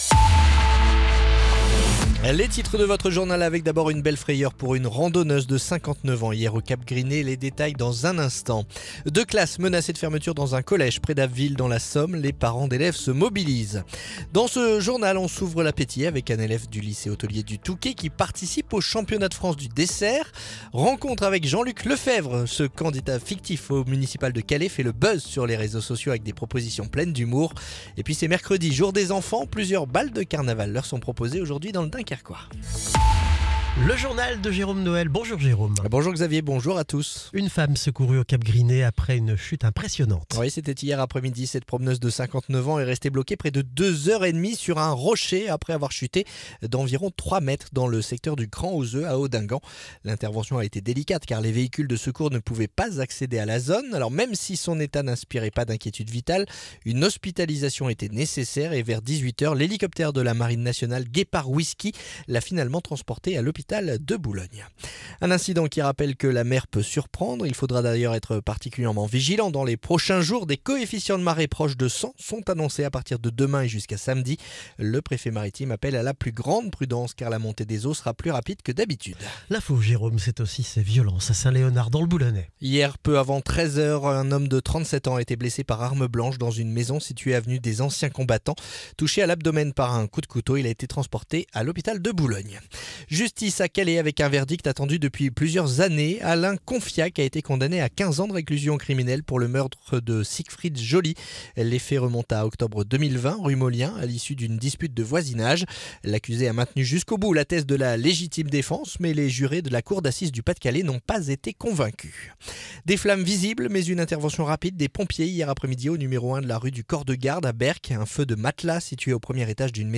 Le journal du mercredi 18 février